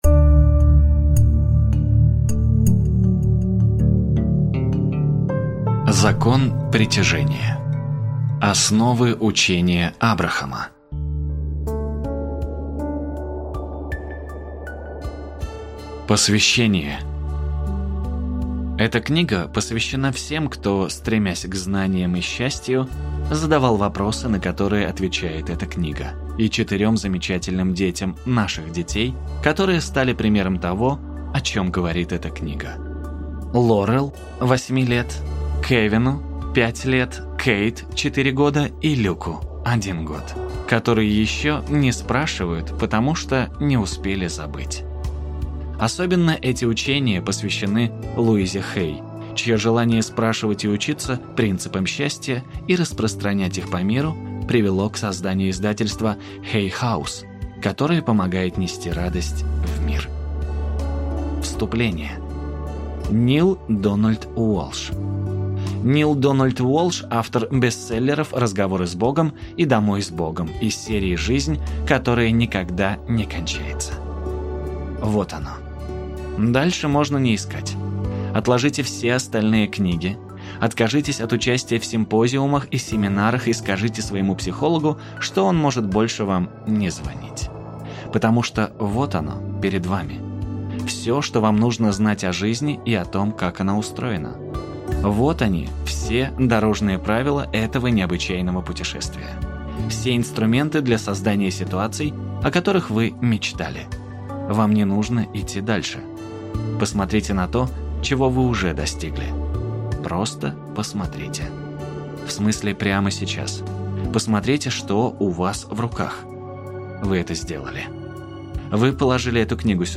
Аудиокнига Закон притяжения + Учение о воплощении желаний в жизнь. Просите – и дано вам будет | Библиотека аудиокниг